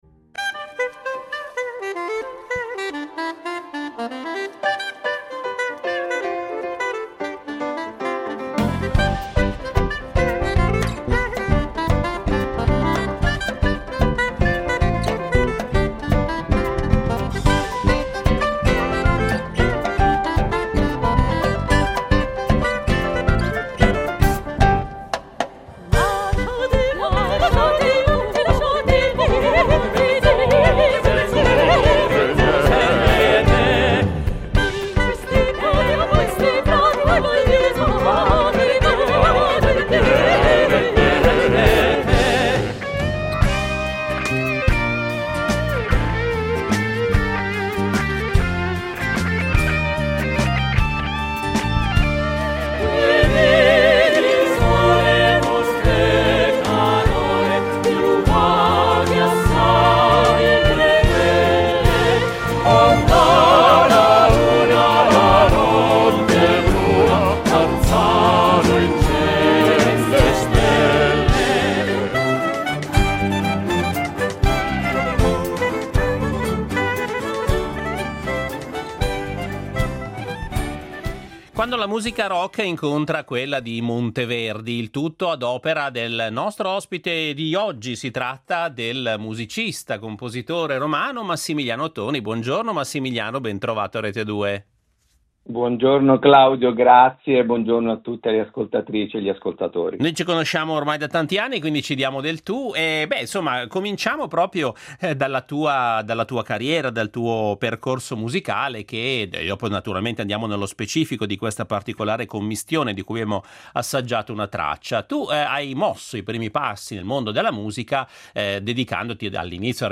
Oggi il nostro salotto estivo musicale di Rete Due ospita il musicista romano